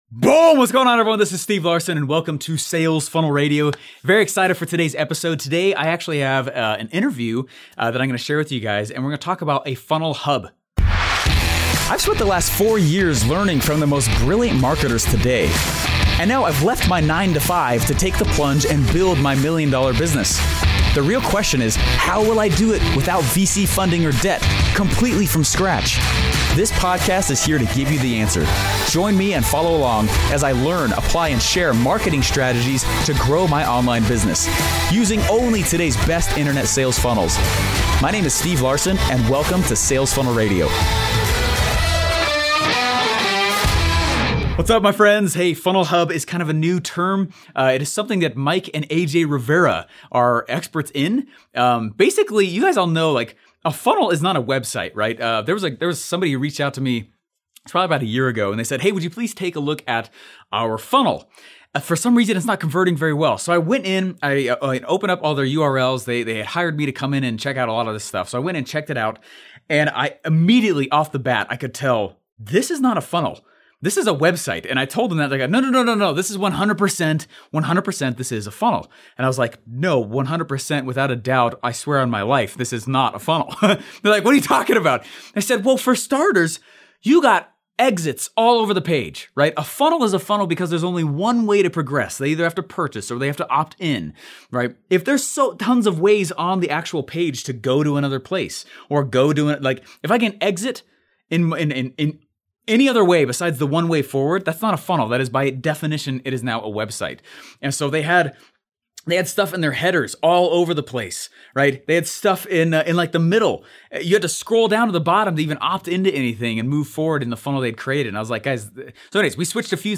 I have two very special guests today and they’ve created what they call a FunnelHub .